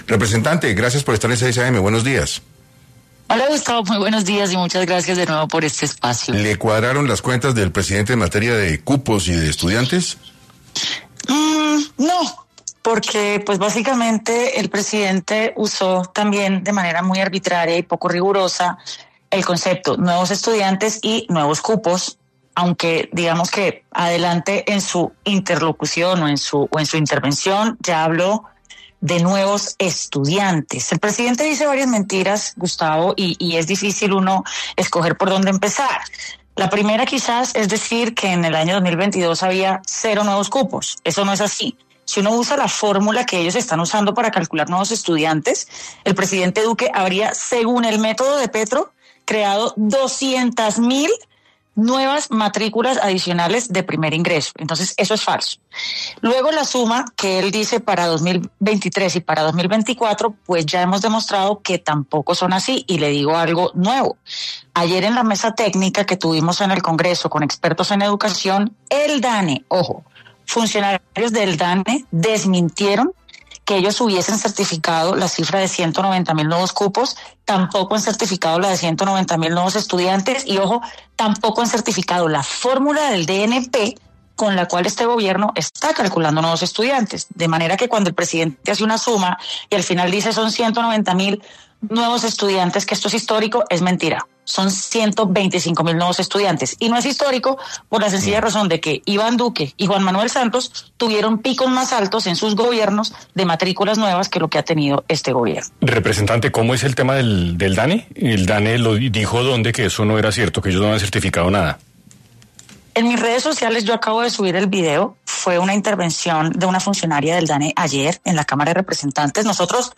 En Caracol Radio estuvo Catherine Juvinao, representante, hablando de la educación en el país.